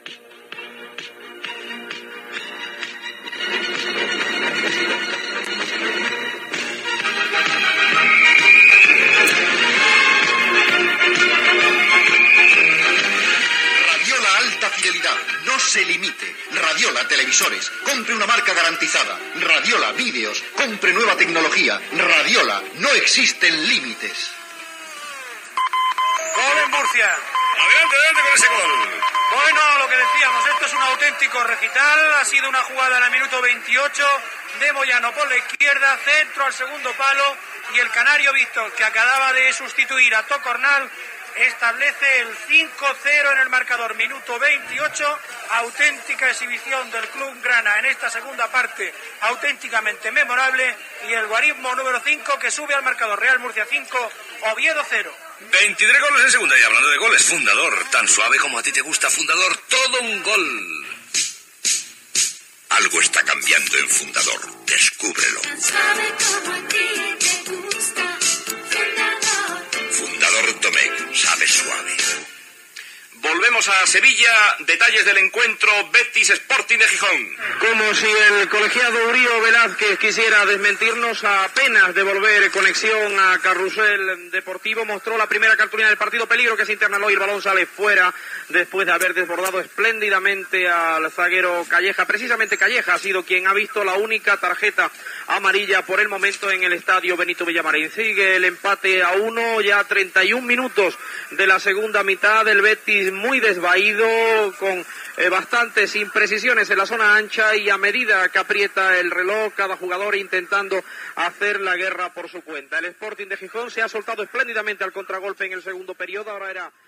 Publicitat, gol al partit Múrcia-Oviedo, publicitat, connexió amb Sevilla amb el partit Betis-Sporting de Gijón.
Esportiu